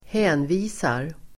Uttal: [²h'ä:nvi:sar]